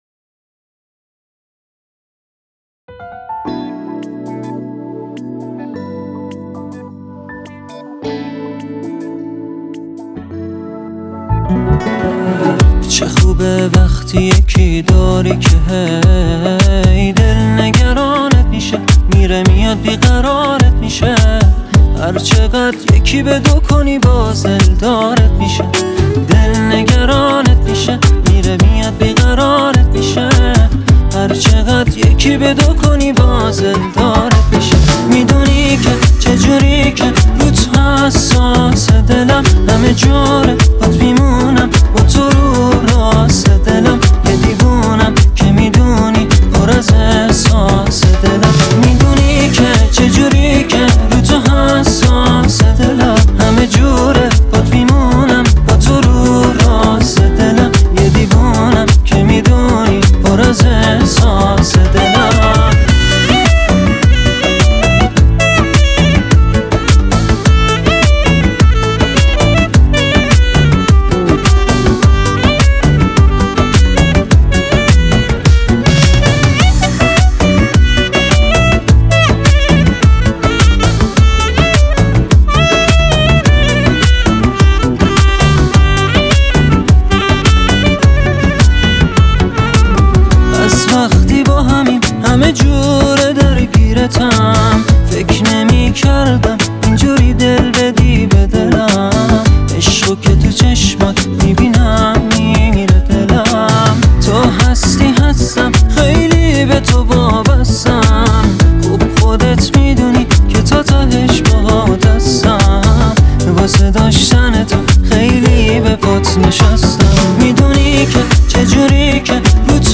پاپ عاشقانه